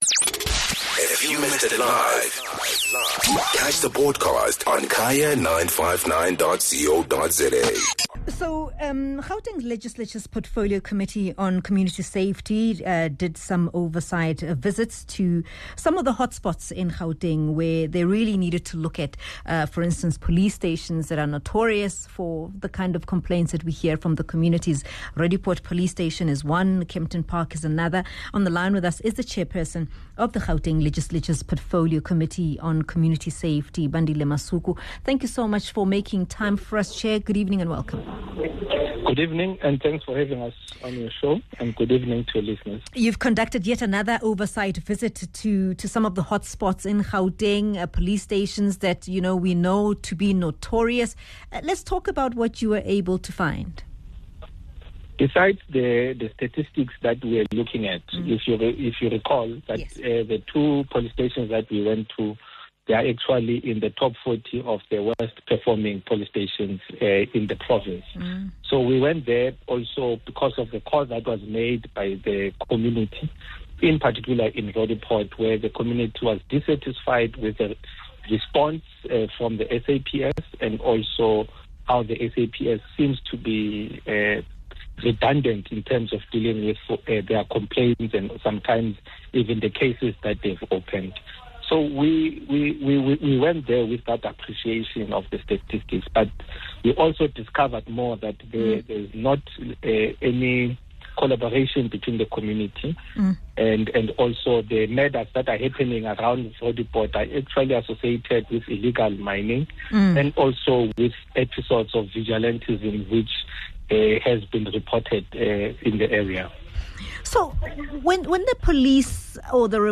Phemelo speaks to the Chairperson of the Gauteng legislature’s portfolio committee on community safety, Dr Bandile Masuku about what their visit uncovered.